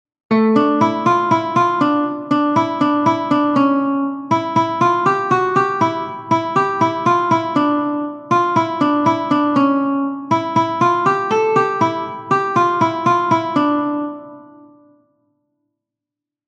Dantzakoa
ABA1B1